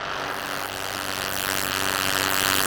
Index of /musicradar/rhythmic-inspiration-samples/90bpm
RI_ArpegiFex_90-01.wav